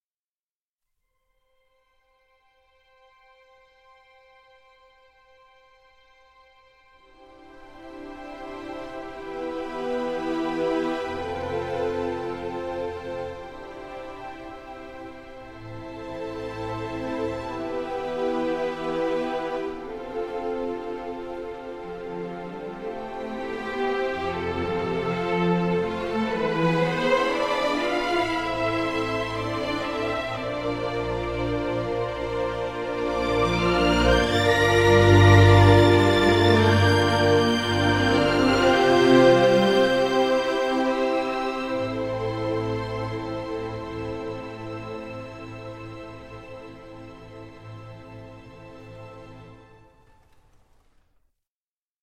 它包含了小提琴、中提琴、大提琴和低音提琴，拥有充满活力的长音和短音，以及一系列更不寻常的演奏技法。
这款音色库是在伦敦一间干净的录音棚中录制的，捕捉了每位演奏家的丰富和细腻的音色。
这些技法可以让你创造出不同的氛围和情感，从温暖而柔和，到紧张而刺耳，从传统而优雅，到现代而前卫。